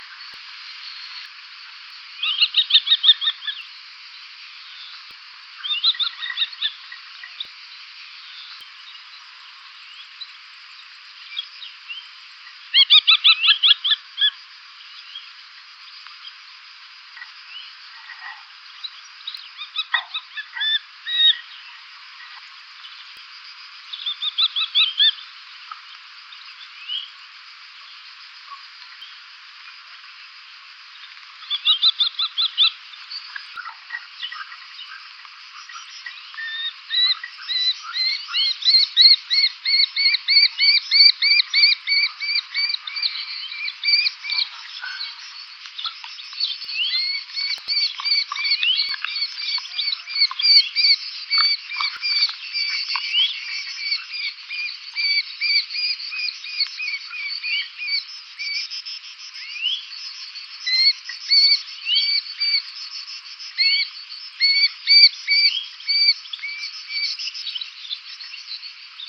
FALCO TINNUNCULUS - KESTREL - GHEPPIO